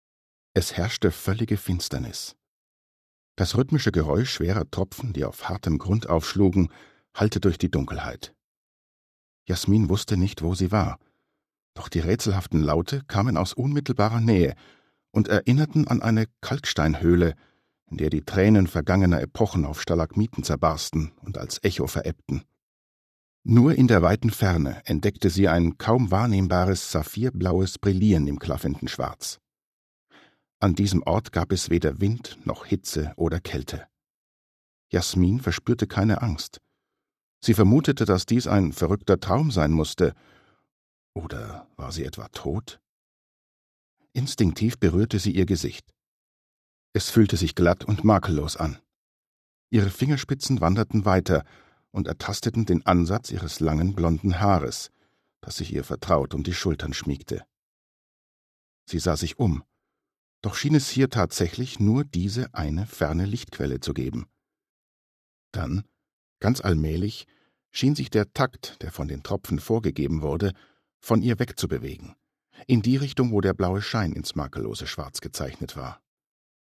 Stimmfarbe: dunkel, mittel, tief